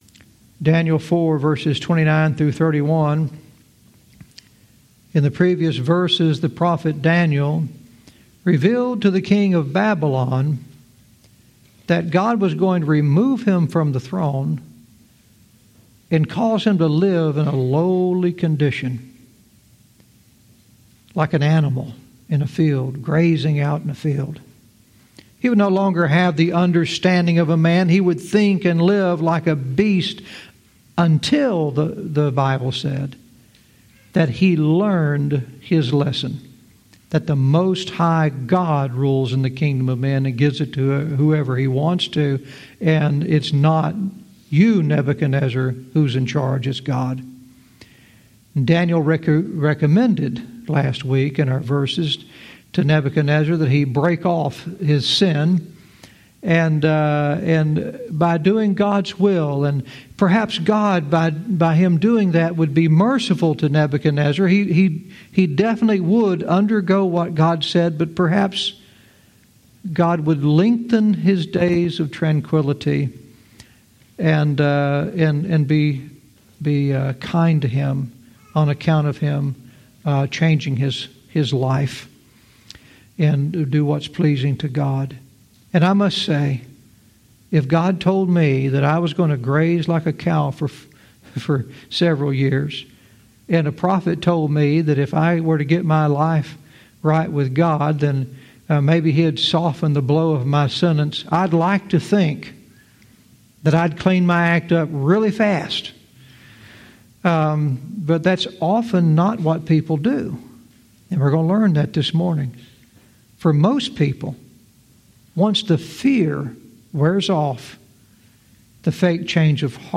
Verse by verse teaching - Daniel 4:29-31 "Who Built Babylon?"